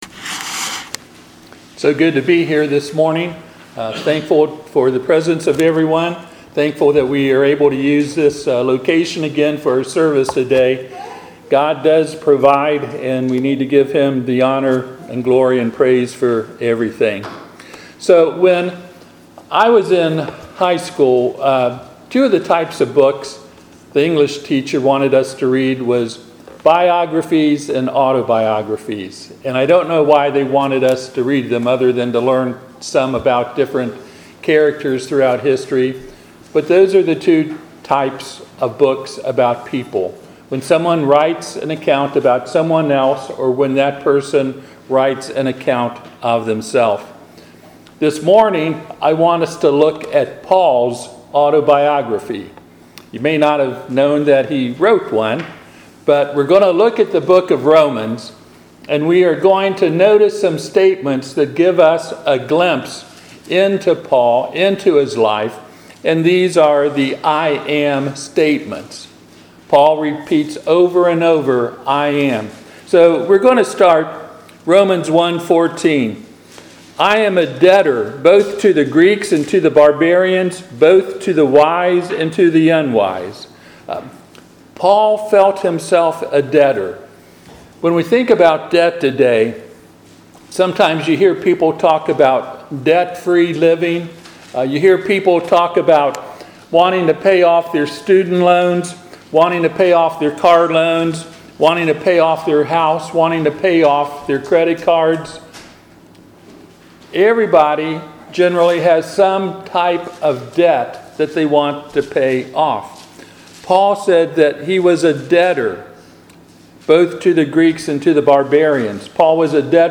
Passage: Romans 1:1-16 Service Type: Sunday AM